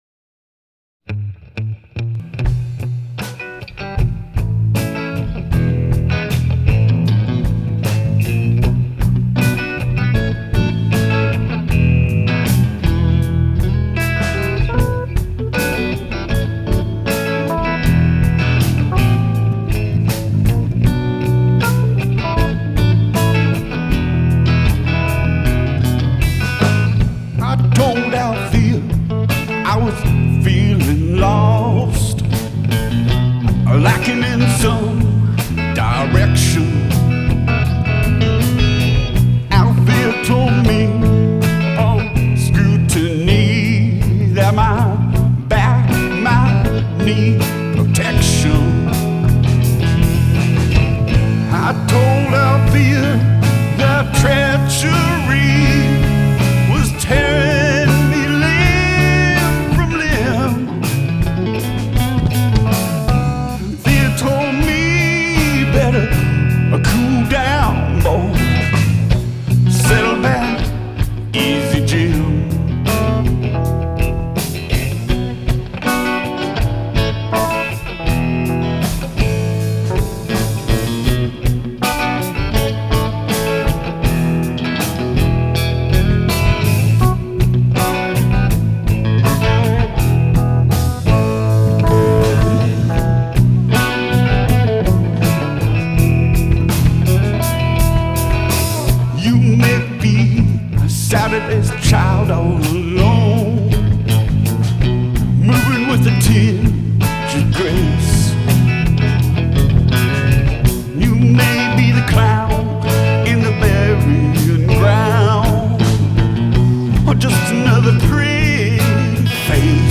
Seattle Peace Concert (Seattle) - 7/11/10